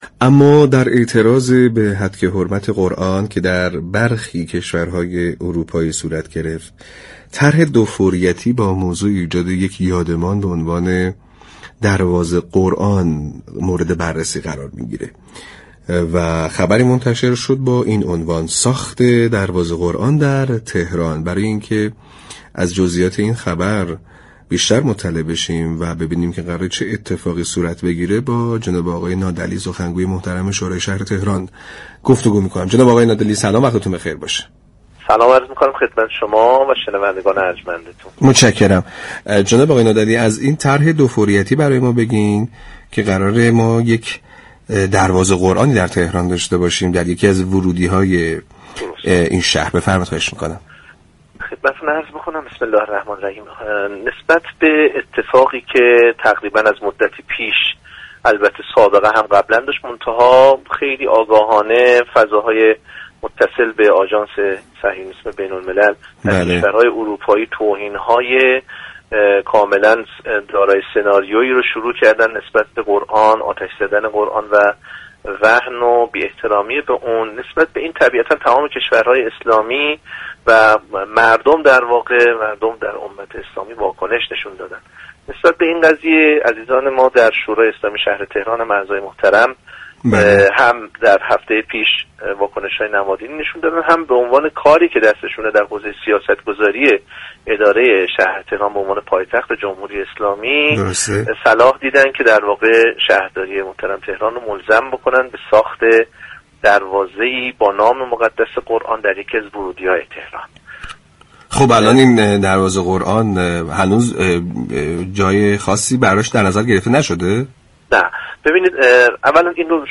به گزارش پایگاه اطلاع رسانی رادیو تهران؛ علیرضا نادعلی سخنگوی شورای شهر تهران در گفتگو با برنامه «سعادت آباد 8 مرداد» در خصوص طرح دو فوریتی ساخت دروازه قرآن تهران به عنوان یك نماد در یكی از ورودی‌های پایتخت گفت: هتك حرمت و توهین‌های آگاهانه و دارای سناریو به قرآن طرحی است كه توسط صهیونیست‌ها در كشورهای اروپایی دنبال شده و كشورها و ملت اسلامی نسبت به آن واكنش نشان داده اند.